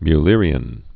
(my-lîrē-ən, mə-, mĭ-)